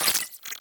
Computer Calculation Notificaiton.wav